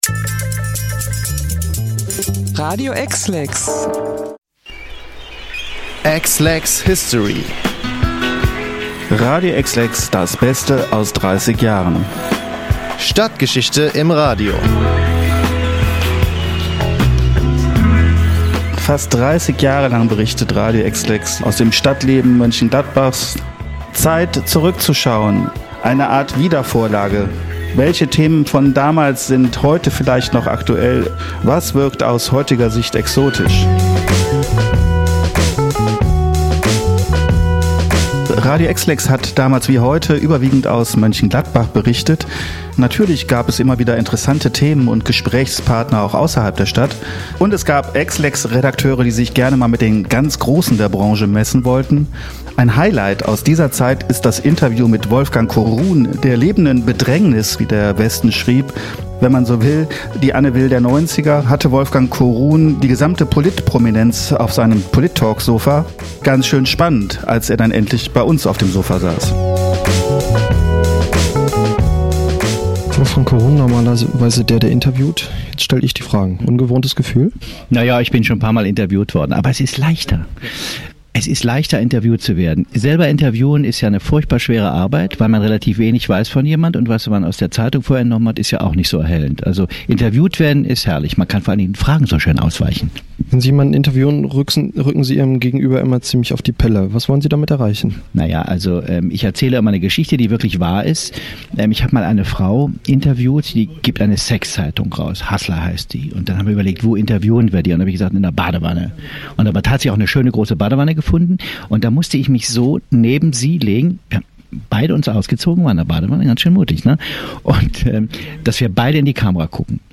Mit der Sendereihe EXLEX-History schöpfen wir aus unserem reichhaltigen Archiv und beleben Beiträge der letzten Jahrzehnte wieder.